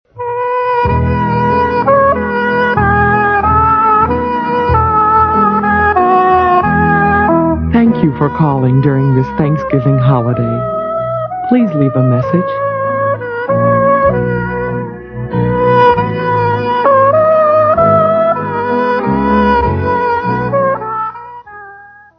Phonies Holiday Telephone Answering Machine Messages